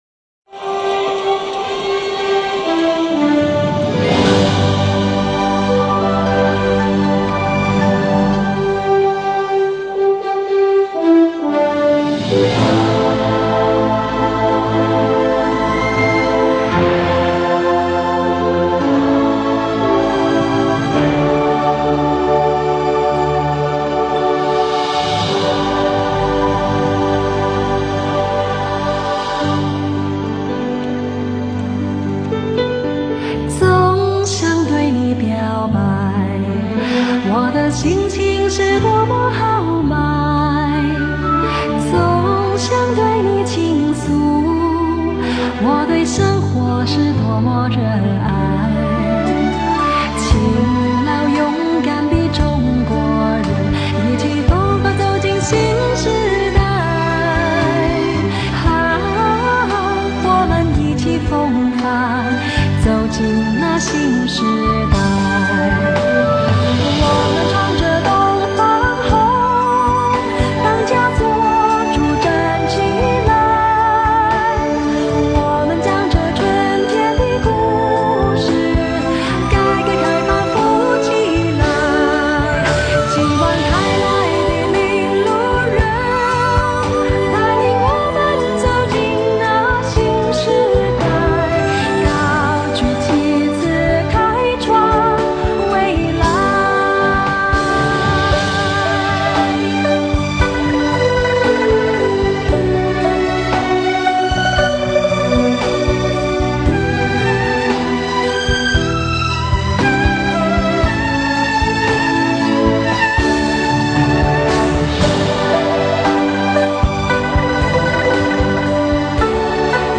因为这是通俗唱法，没有那种豪气；
温婉而轻柔的演绎，俗唱却不俗；